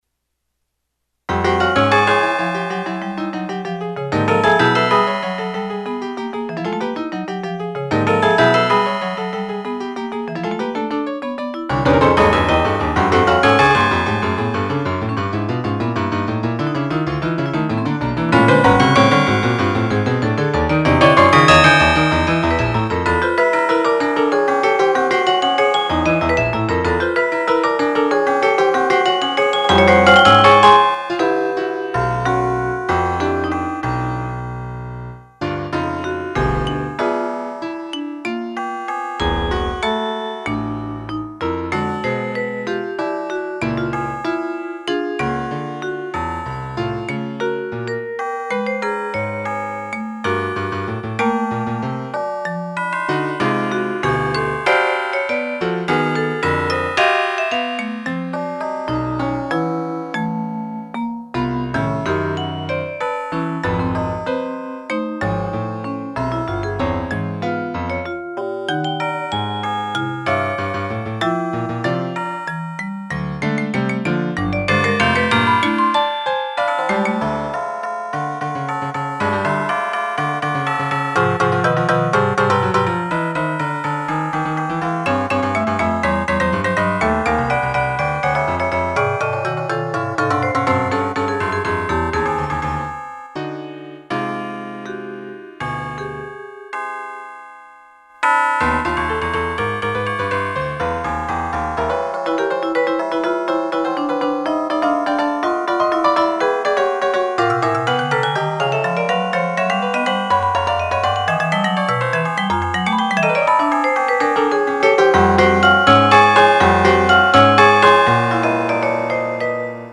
CONTEMPORARY MUSIC ; POLYPHONIC MUSIC